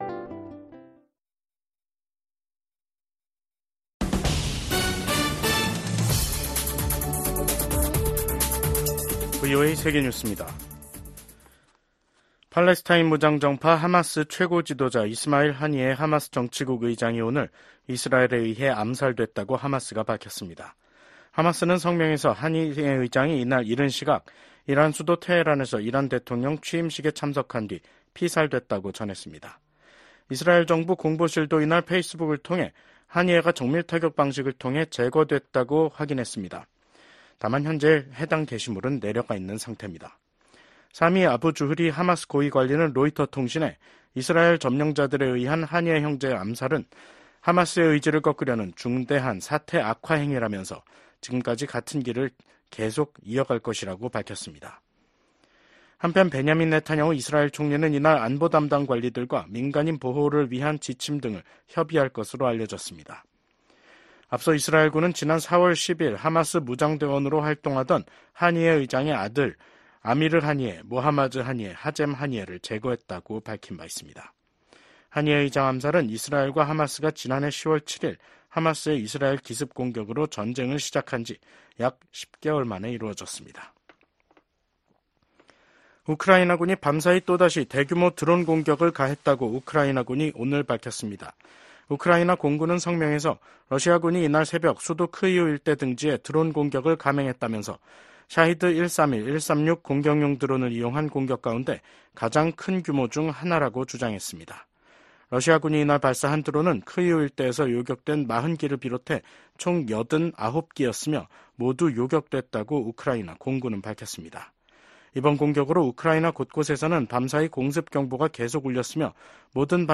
VOA 한국어 간판 뉴스 프로그램 '뉴스 투데이', 2024년 7월 31일 2부 방송입니다. 중국과 러시아가 북한에 대한 영향력을 놓고 서로 경쟁하고 있다고 미국 국무부 부장관이 평가했습니다. 최근 몇 년간 중국, 러시아, 이란, 북한간 협력이 심화돼 미국이 냉전 종식 이후 가장 심각한 위협에 직면했다고 미국 의회 산하 기구가 평가했습니다.